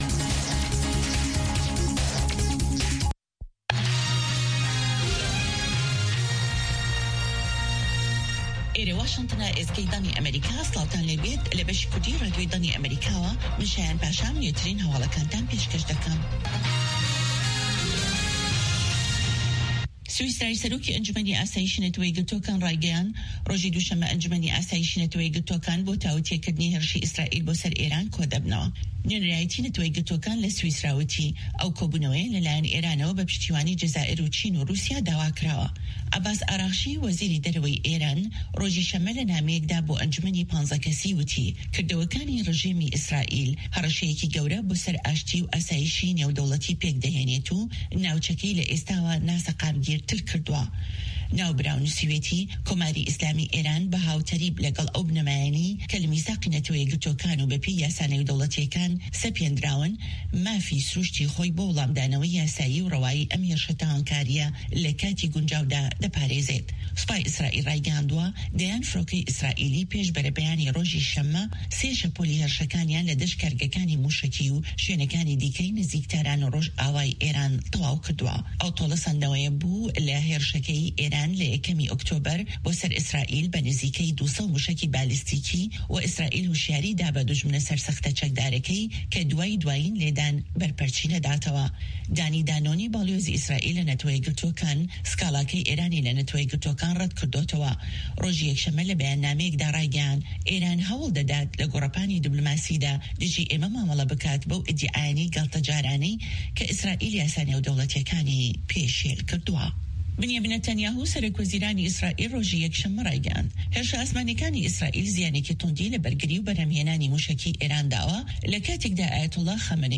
Nûçeyên Cîhanê 1
Nûçeyên Cîhanê ji Dengê Amerîka